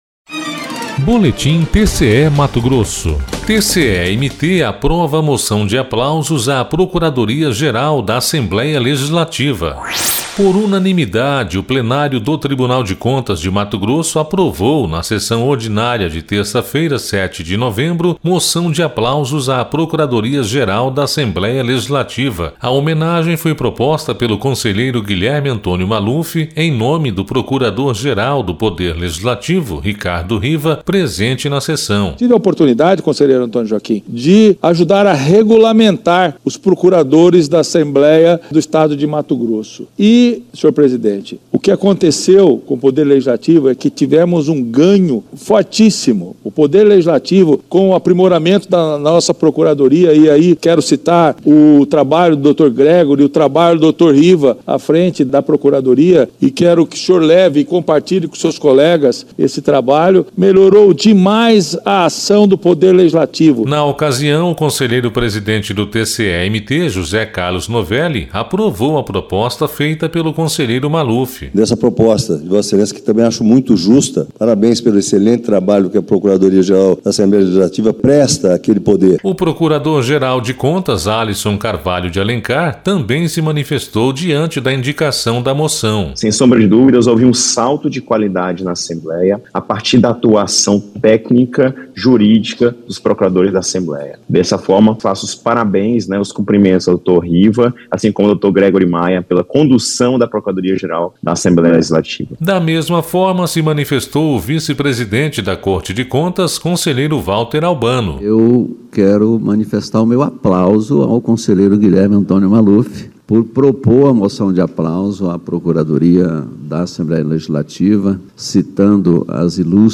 Sonora: Guilherme Antonio Maluf – conselheiro do TCE-MT
Sonora: José Carlos Novelli – conselheiro presidente do TCE-MT
Sonora: Alisson Carvalho de Alencar - procurador-geral de Contas
Sonora: Valter Albano – conselheiro vice-presidente do TCE-MT